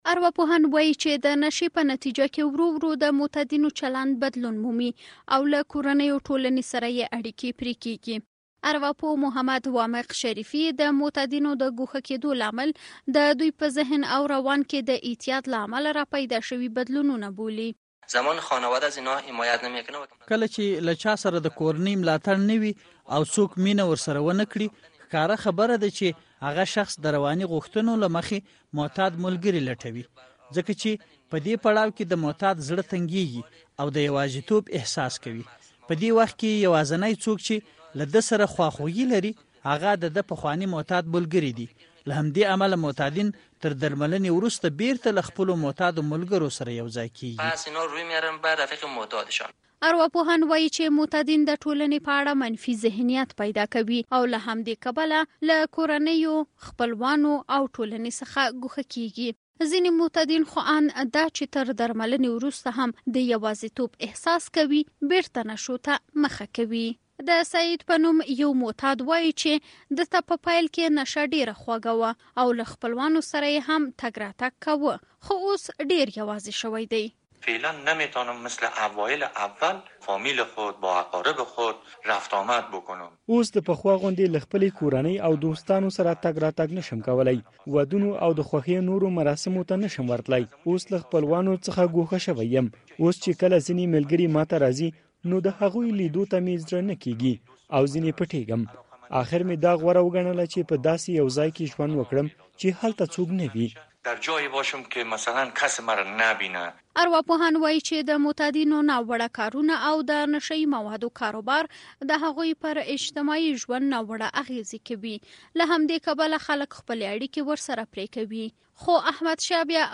غږیز راپور